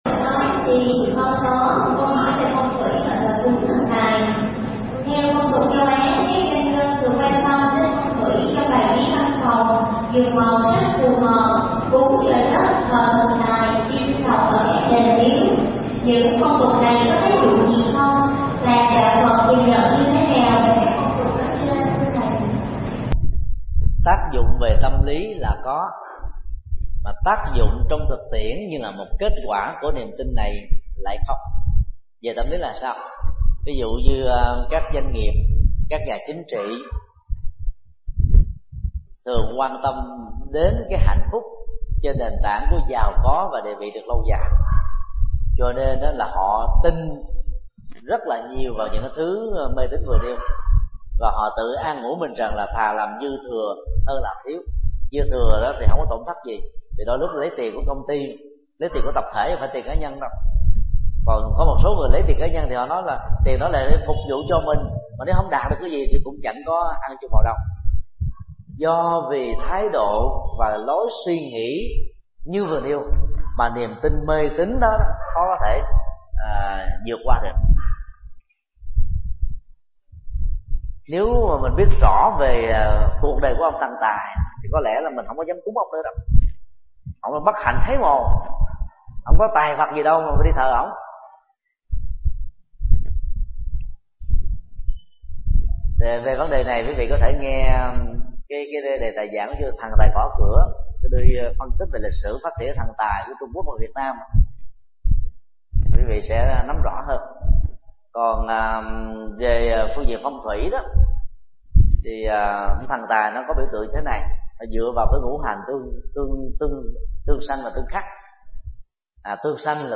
Vấn đáp: Phong thủy, cúng thần tài